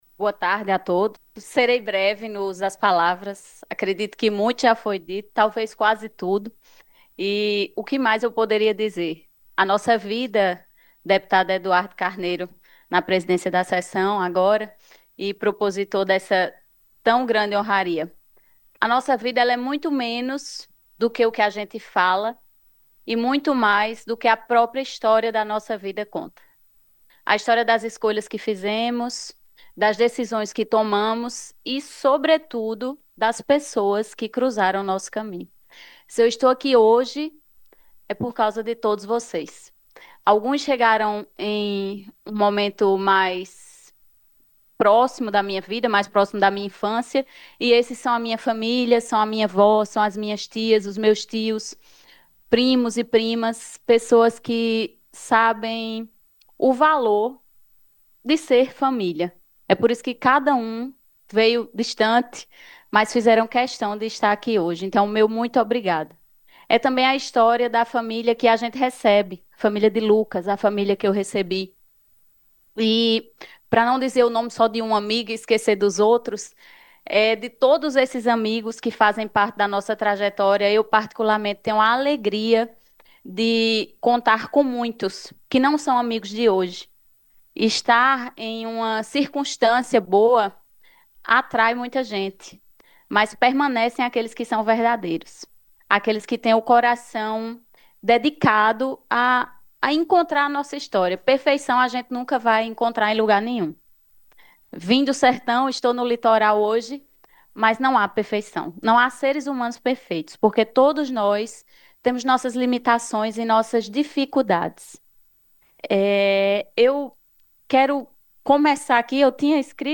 A sessão solene realizada na última quinta-feira (12) na Assembleia Legislativa da Paraíba foi marcada por um momento de forte emoção.